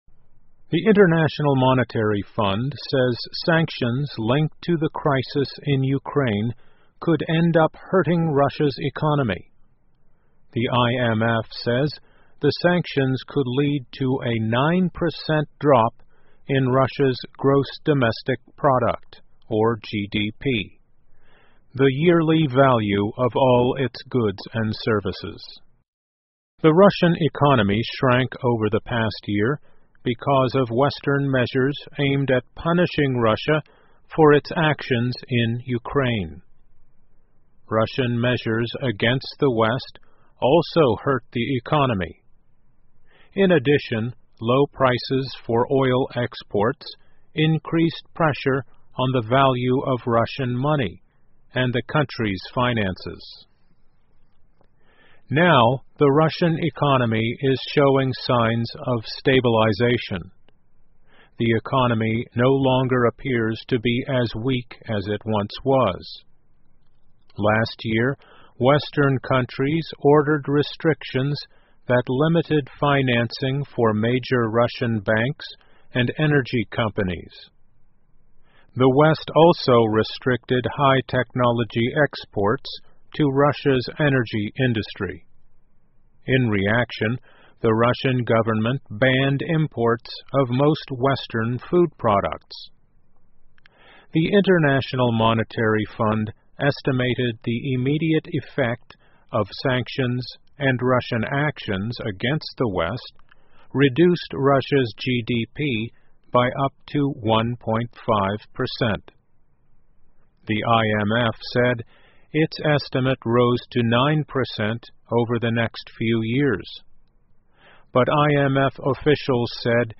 在线英语听力室国际货币基金组织:西方制裁可能会损害俄罗斯经济的听力文件下载,2015年慢速英语(八)月-在线英语听力室